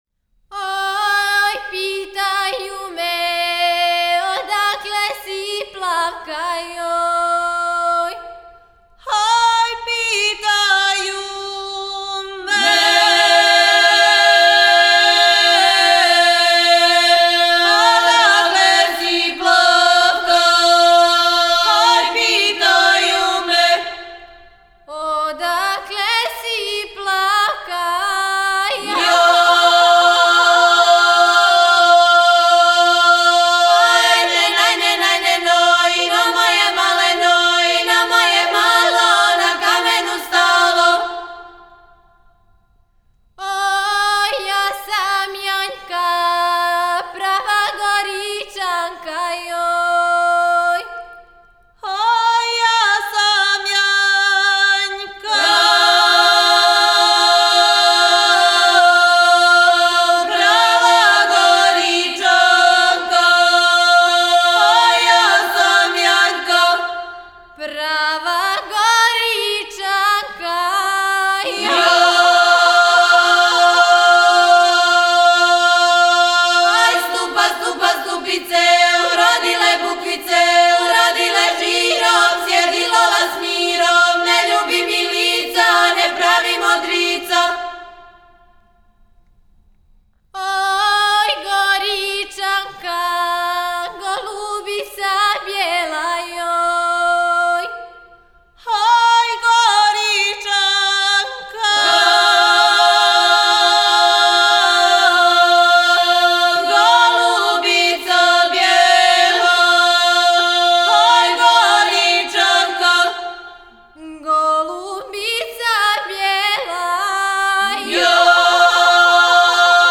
Напомена: "Јањски начин пјевања", забележен од колониста из Босанске Крајине насељених у Обровцу у Бачкој